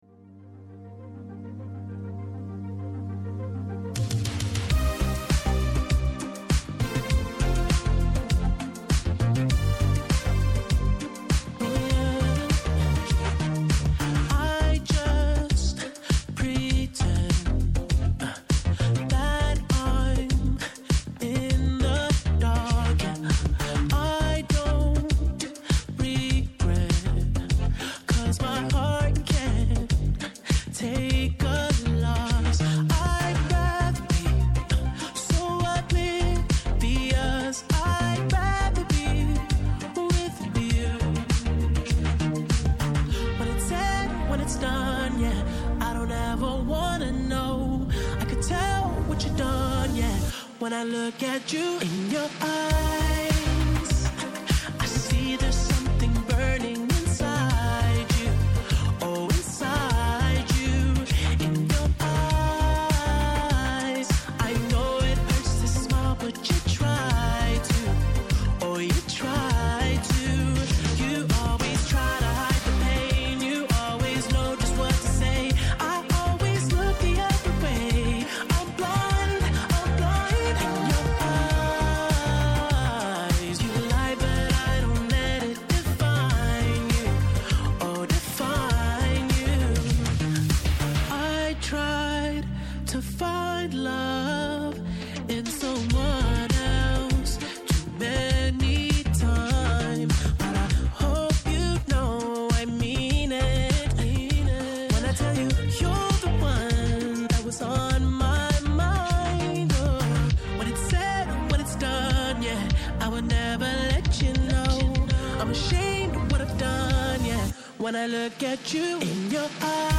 Σήμερα 9-10 το πρωί καλεσμένοι :
-Ο Άγγελος Συρίγος, βουλευτής ΝΔ και Αναπληρωτής καθηγητής Διεθνούς Δικαίου και Εξωτερικής Πολιτικής στο Πάντειο Πανεπιστήμιο
-Ο Νίκος Παππάς, Κοινοβουλευτικός εκπρόσωπος – Τομεάρχης Οικονομικών ΣΥΡΙΖΑ